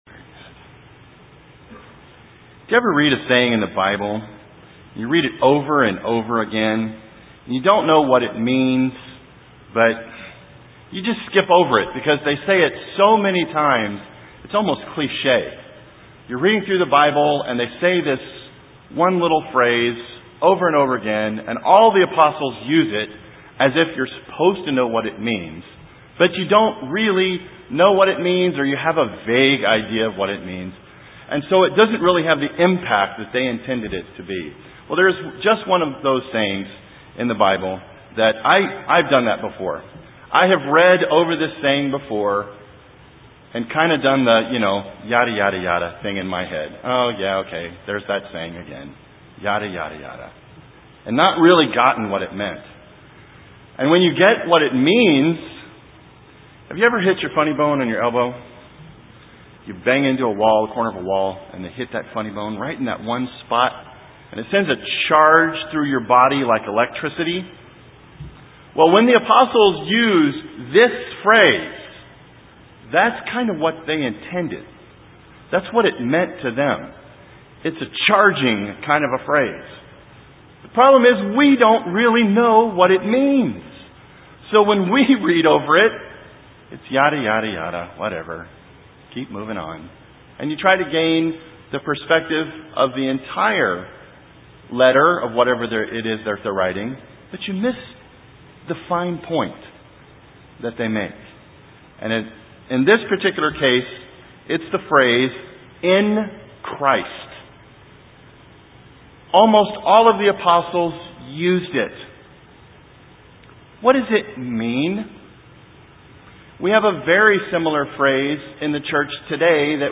This sermon helps us understand what it means to be in Christ Jesus?